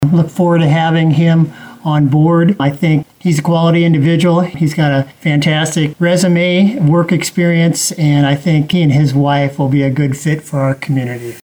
Humboldt, IA – The new City Administrator of the City of Humboldt was introduced at Monday’s City Council meeting.
Humboldt Mayor Dan Scholl introduced Bockelman to the public on Monday.
Dan-Scholl-on-Bockelmann-hiring.mp3